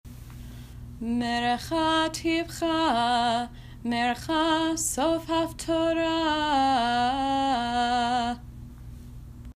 Cantillation for Haftarah
10._mercha_tipcha_mercha_sof_haftorah.mp3